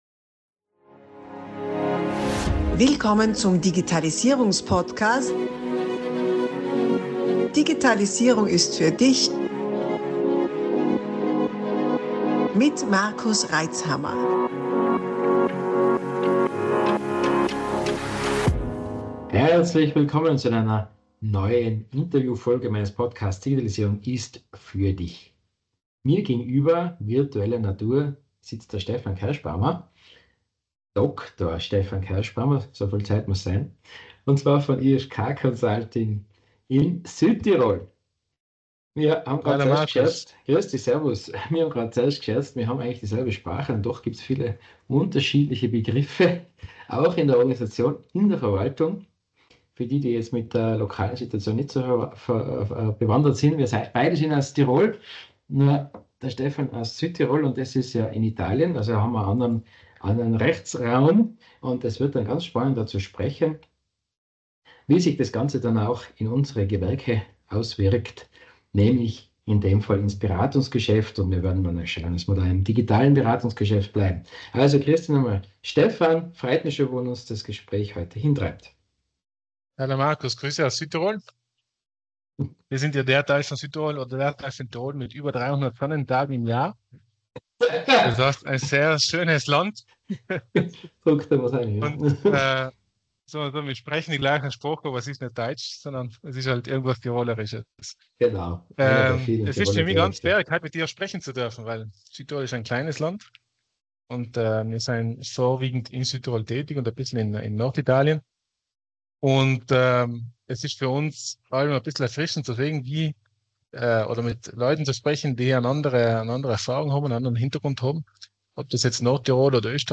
Ideen, wie Du die Digitalisierung für Dich, Dein Unternehmen, Deine Mitarbeiter und Deine Kunden zum Positiven einsetzen kannst. Spannende Interviews mit Unternehmern und Selbständigen zum Thema Digitalisierung.